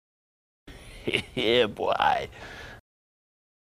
yeah-boy_3RAdFE4.mp3